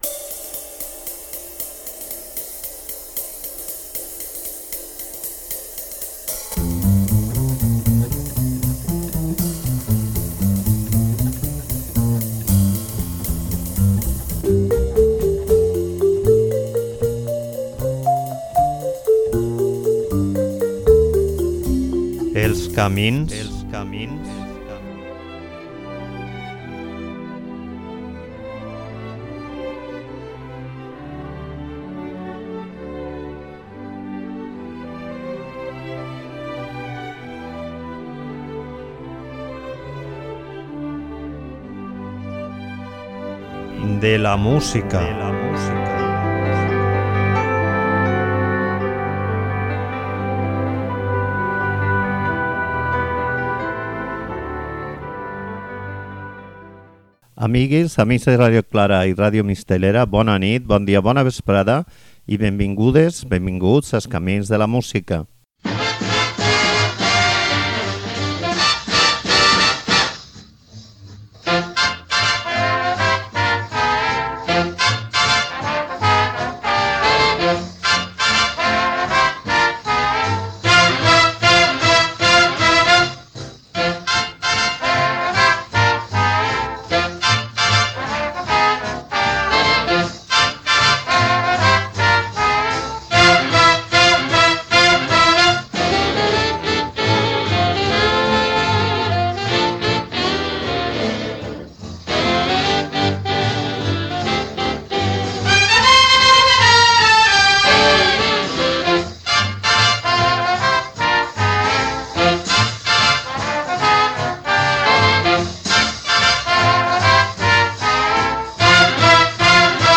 En el resultat es nota la força i la creativitat de tot eixe grapat de músics que anaven a capgirar el món i els sons del jazz, fent una música d'avantguarda dintre d'un món, com el de les orquestres de swing, normalment no massa donades als experiments i molt preocupades per l'èxit comercial.